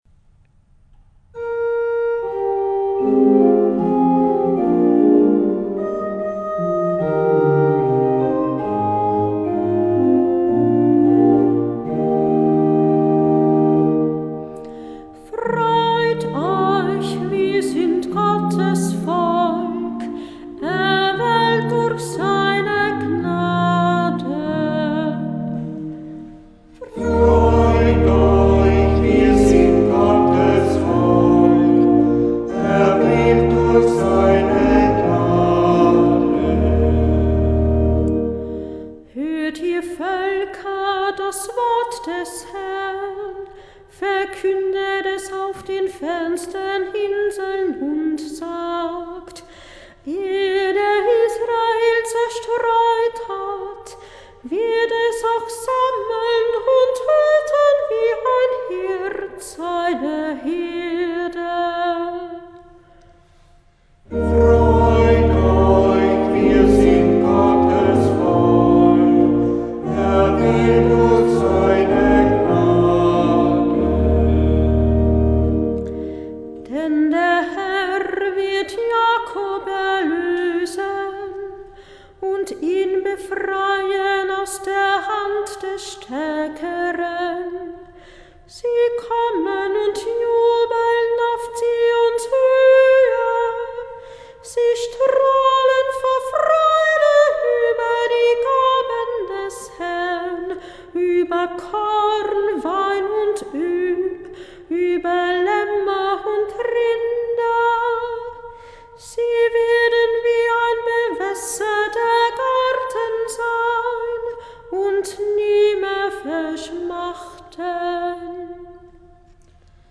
Antwortpsalmen April 2014
S. 134 Orgel
Kantorin